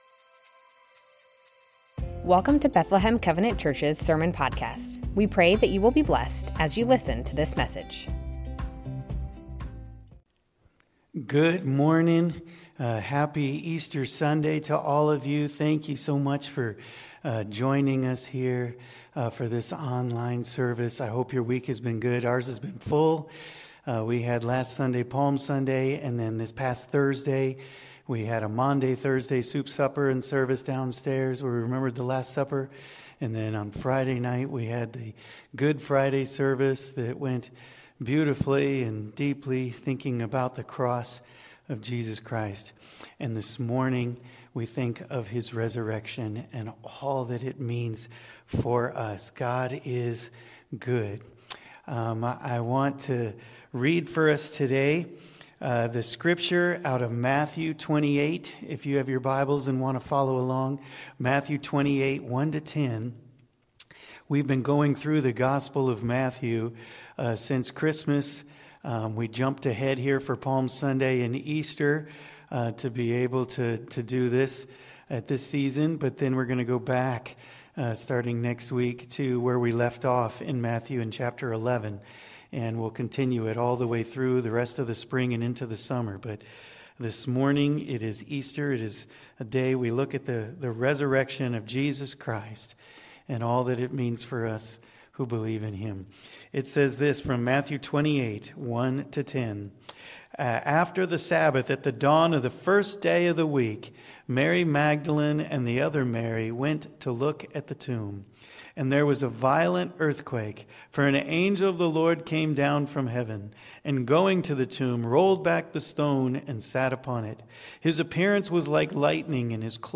Bethlehem Covenant Church Sermons The Resurrection of our Lord - Matthew 28:1-10 Apr 20 2025 | 00:31:00 1x 00:00 / 00:31:00 Subscribe Share Spotify RSS Feed Share Link Embed